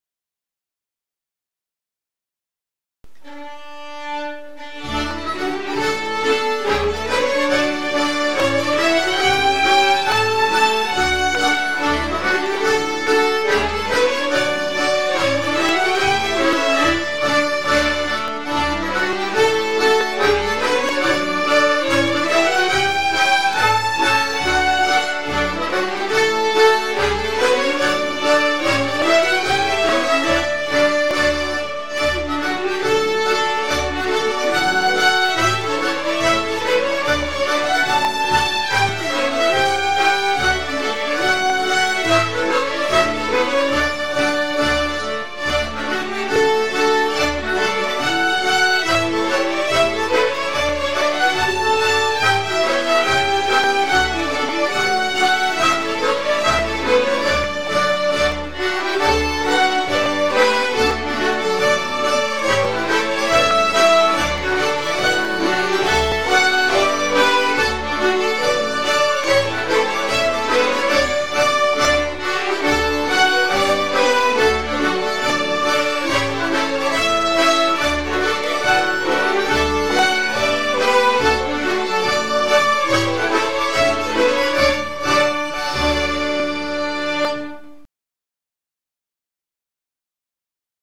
Allspelslåtar Slottsskogsstämman 2025